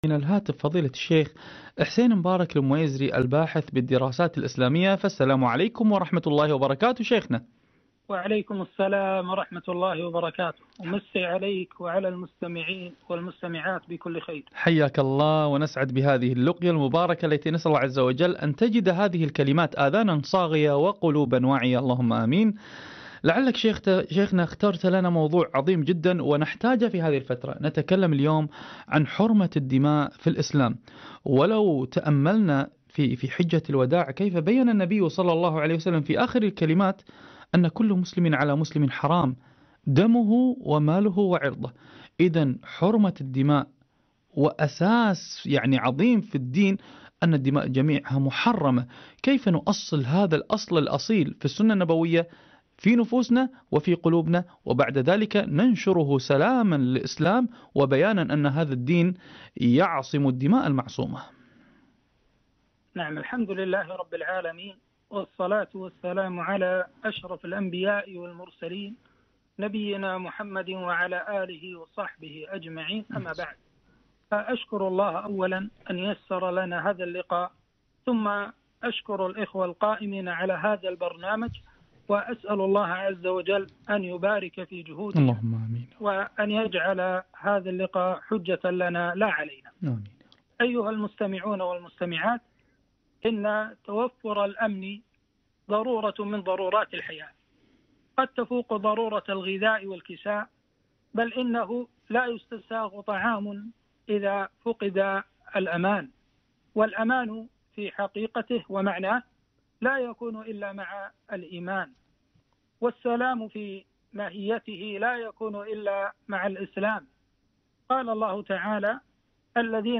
حرمة الدماء في الإسلام - لقاء إذاعي